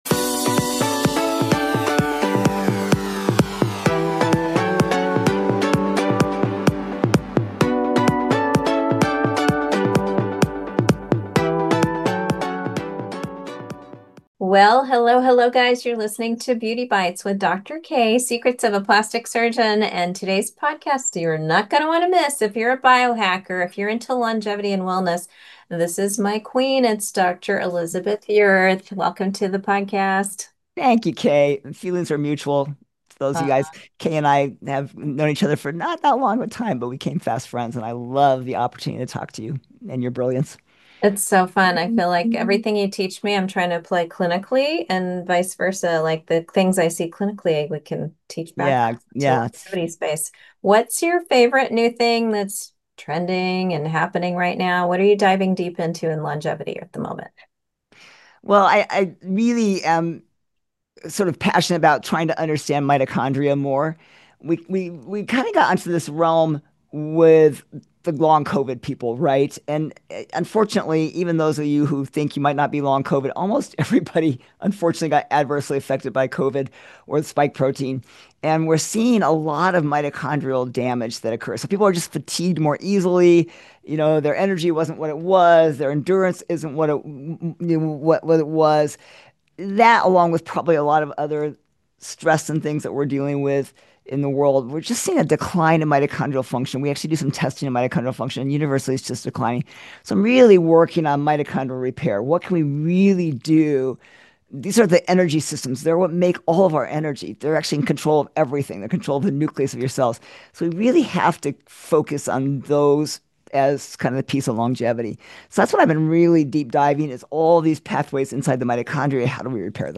Finally, we explore the game-changing role of plasmalogens for insulating our nerves against brain fog and why we are moving away from Metformin to protect our muscle gains . Guest Information: